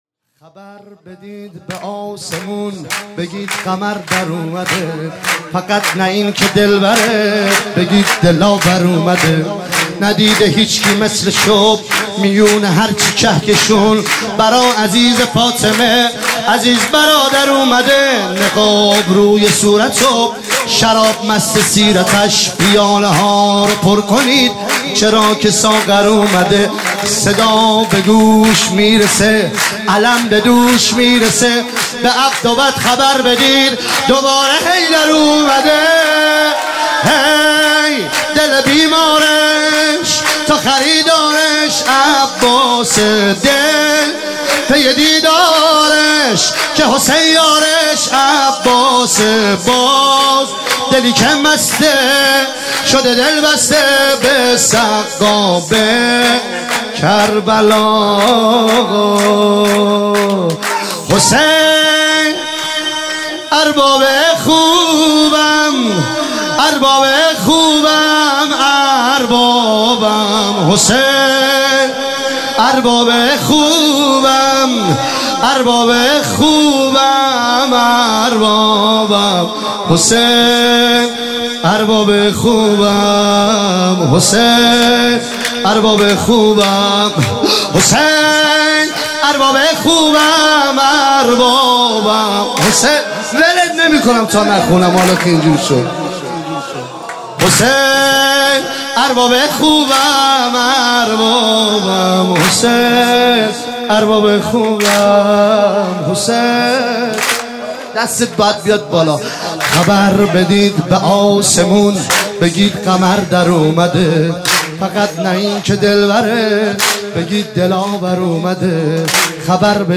عنوان ولادت سرداران کربلا – شب دوم
سرود